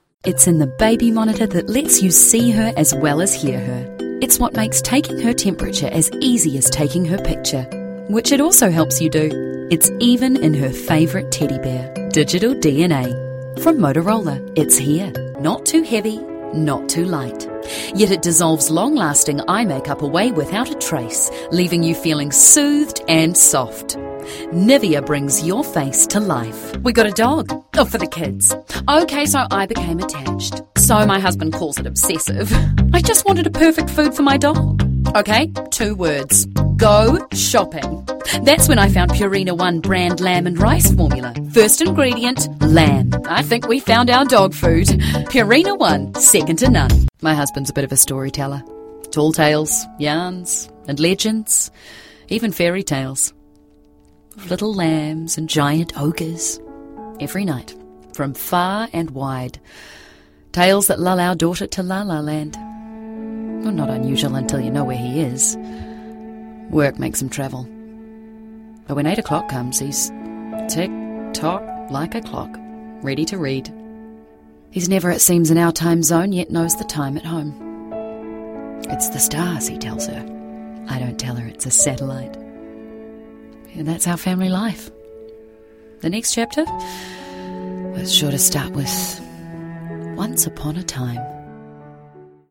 Demo
Adult
new zealand | natural
COMMERCIAL 💸
NARRATION 😎
smooth/sophisticated
warm/friendly
documentary